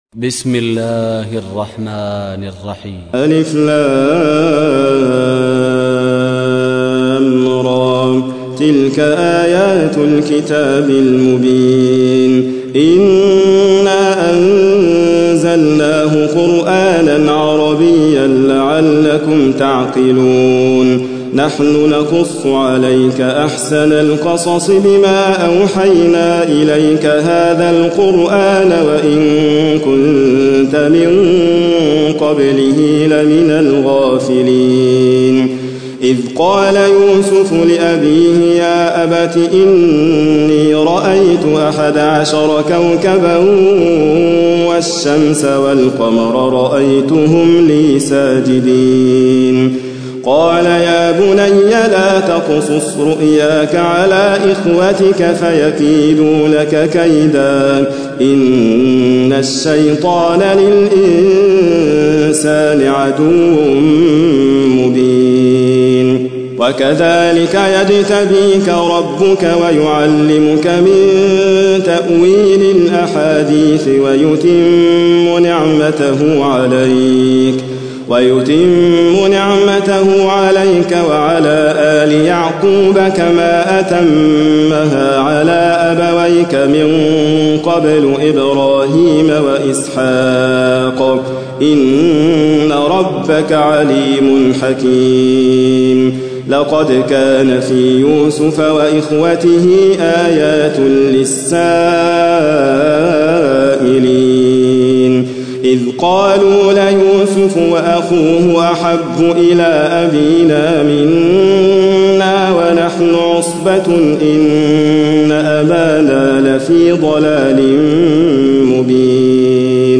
تحميل : 12. سورة يوسف / القارئ حاتم فريد الواعر / القرآن الكريم / موقع يا حسين